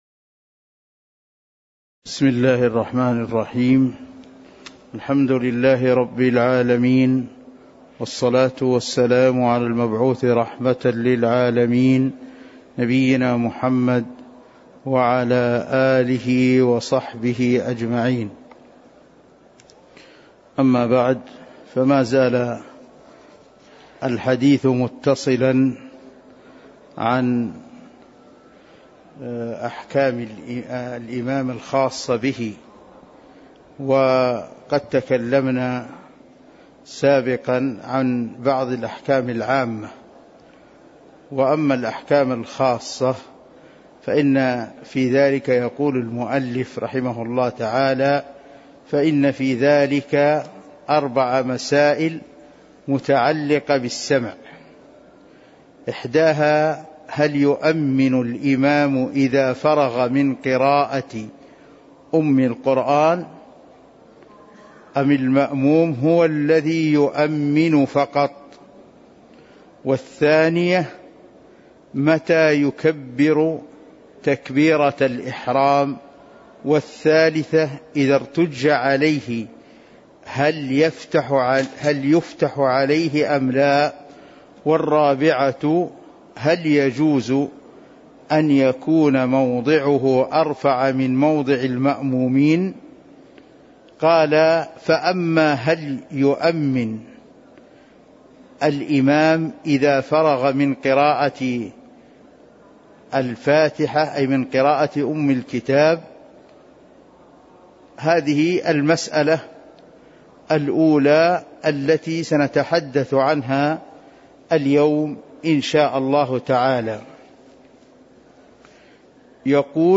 تاريخ النشر ٢٦ صفر ١٤٤٣ هـ المكان: المسجد النبوي الشيخ